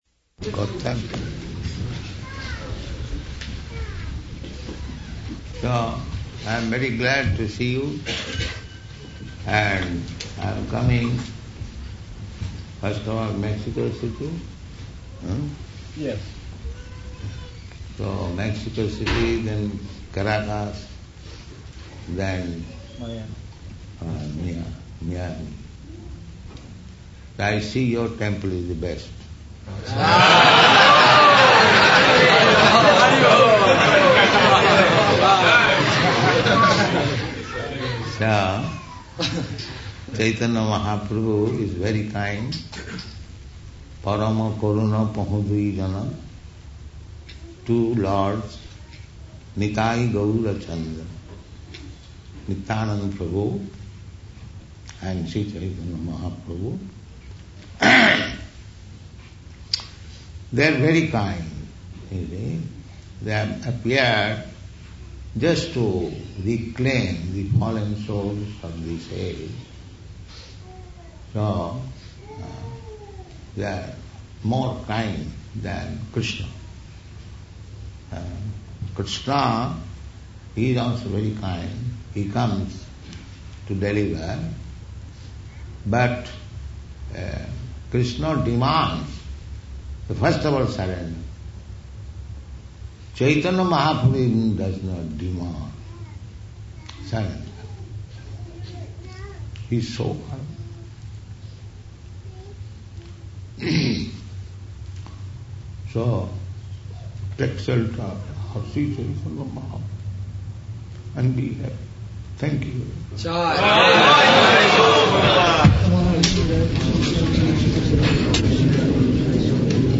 Srila Prabhupada Arrival Address Atlanta February 28, 1975.